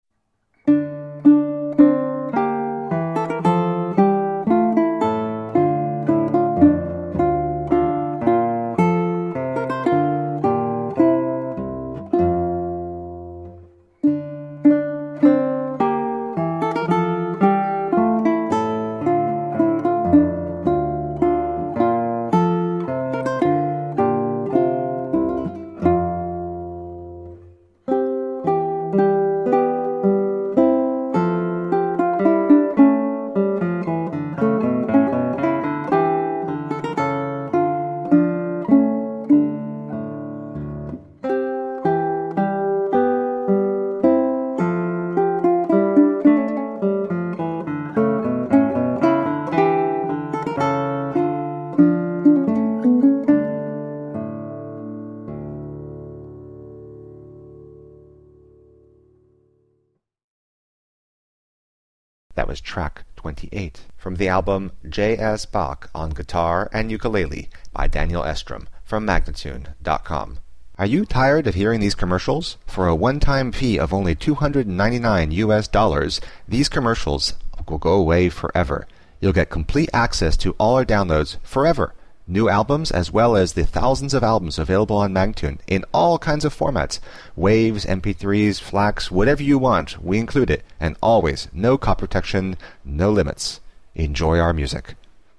Classical, Baroque, Instrumental
Classical Guitar, Ukulele